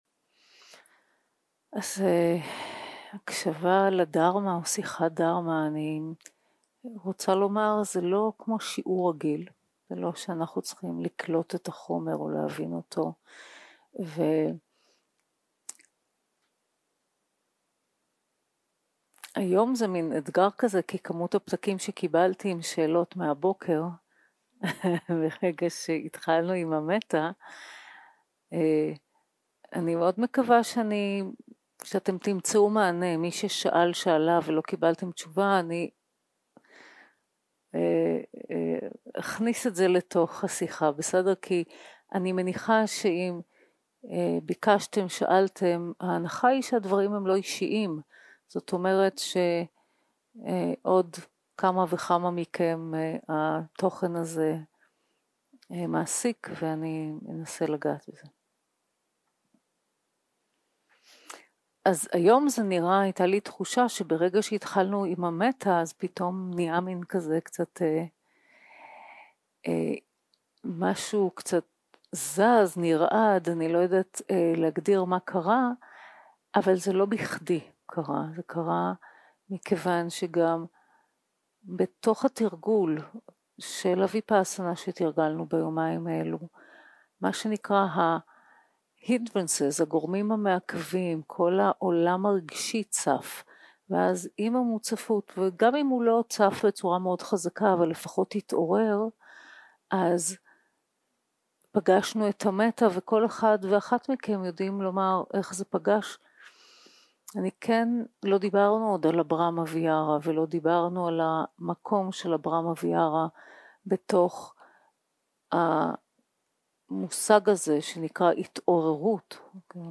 יום 4 - הקלטה 9 - ערב - שיחת דהרמה - ברהמה ויהארה
Dharma type: Dharma Talks